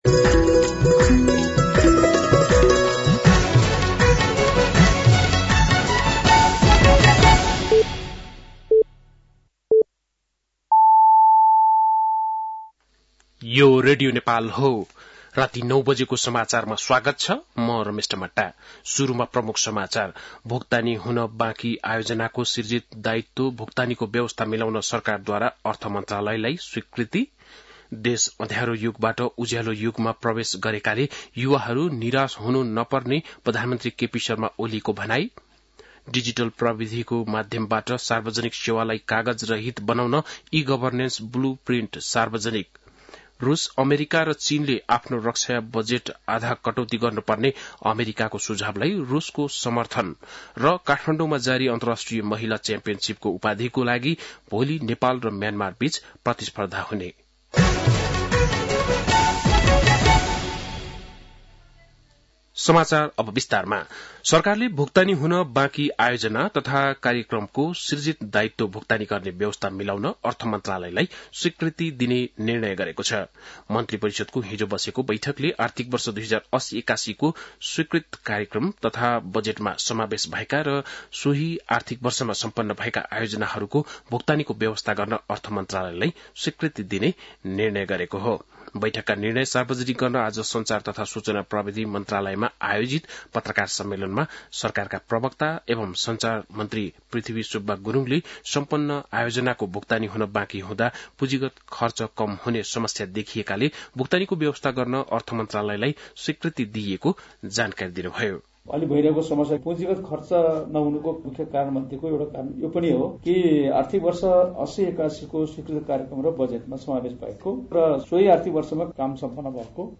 बेलुकी ९ बजेको नेपाली समाचार : १४ फागुन , २०८१